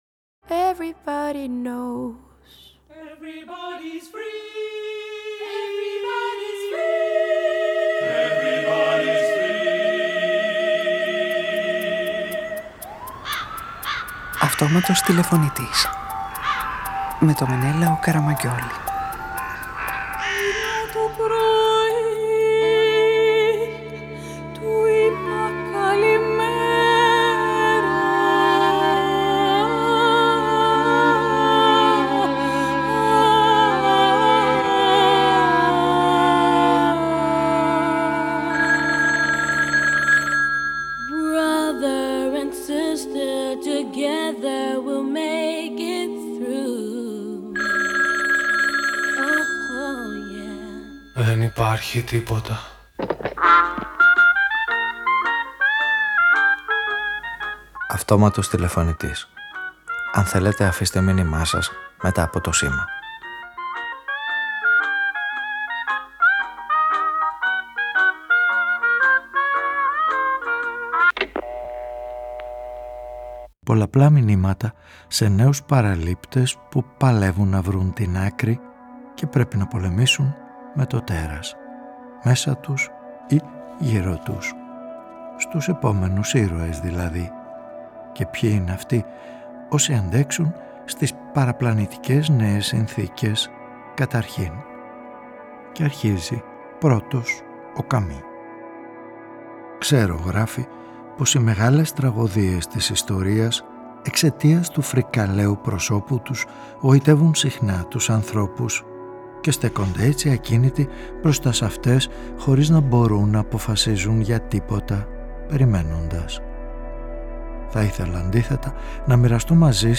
Ραδιοφωνικη Ταινια